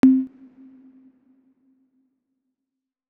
ButtonOver1.wav